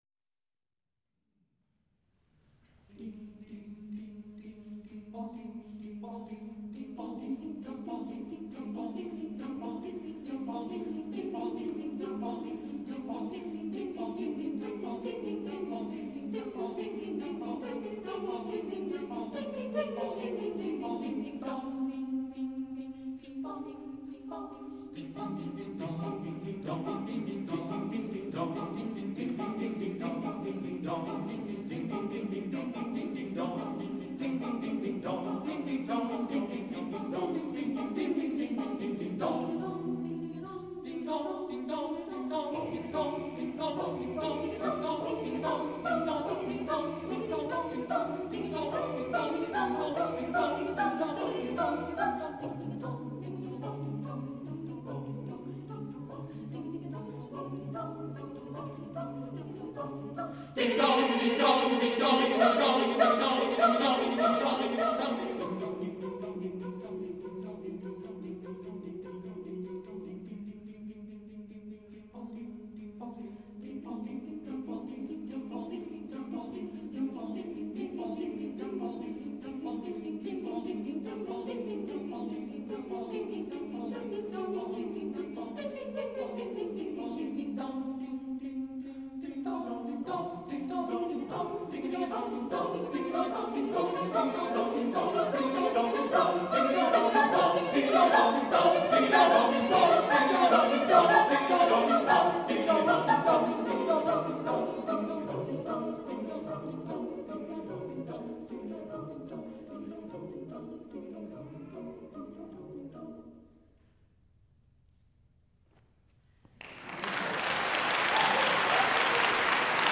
csucs - Music from the CSU Chamber Singers while I was a member
2005 Fall Concert